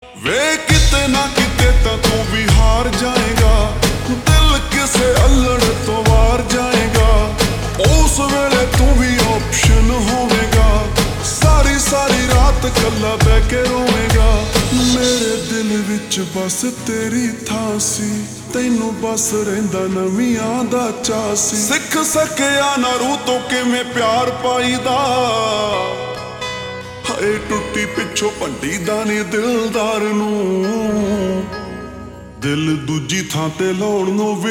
Punjabi Songs
( Slowed + Reverb)